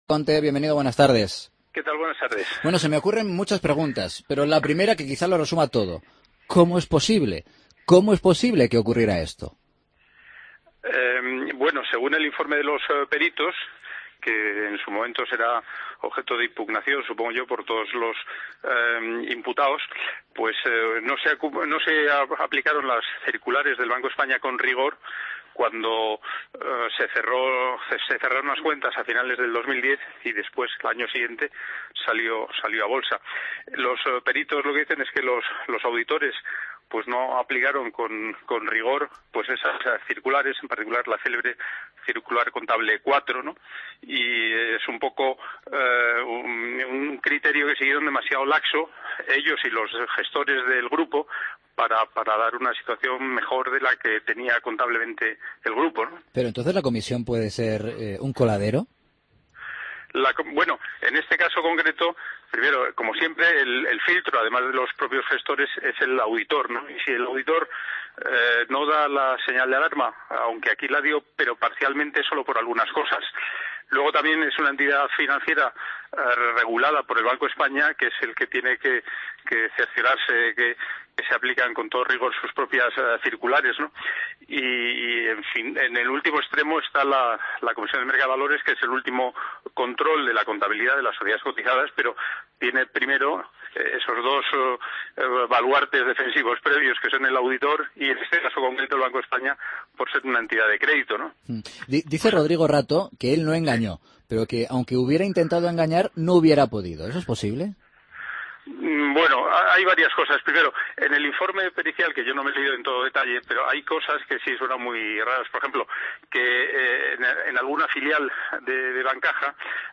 AUDIO: Escucha la entrevista a Manuel Compte, ex presidente de la Comisión Nacional del Mercado de Valores, en Mediodía COPE